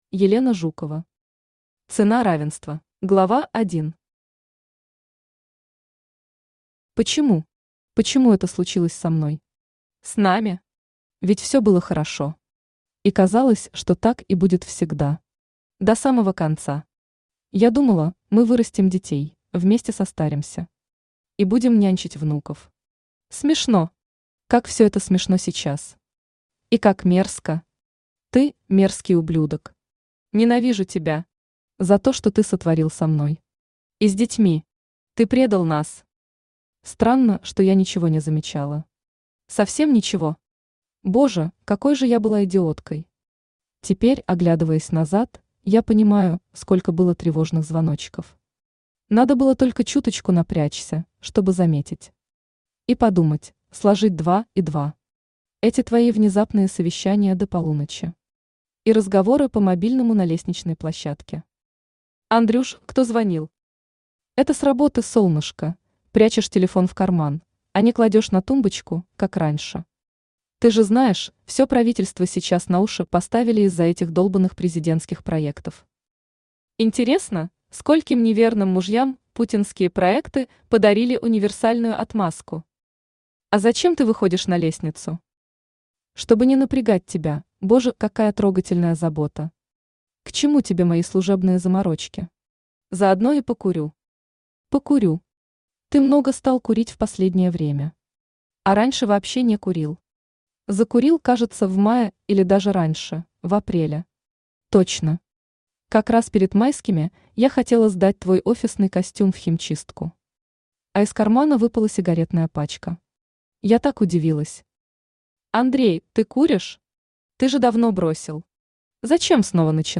Аудиокнига Цена равенства | Библиотека аудиокниг
Aудиокнига Цена равенства Автор Елена Жукова Читает аудиокнигу Авточтец ЛитРес.